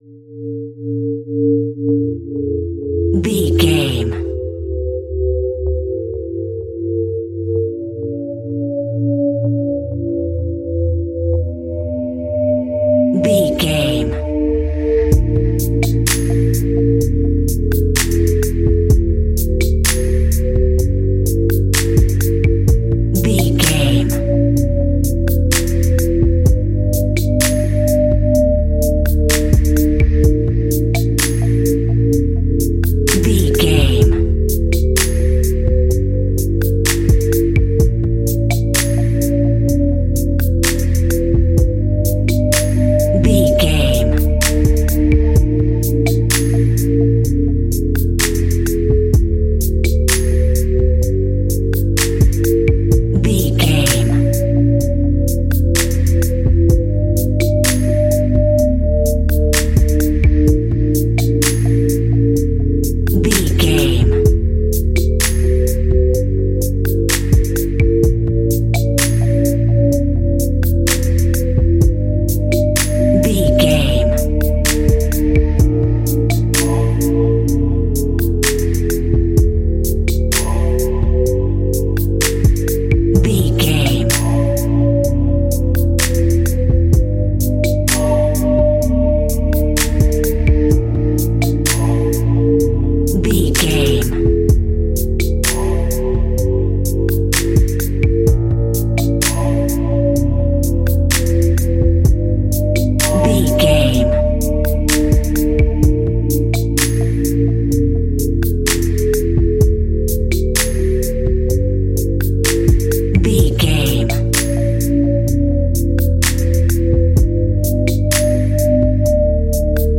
Ionian/Major
B♭
Slow
chilled
laid back
hip hop drums
hip hop synths
piano
hip hop pads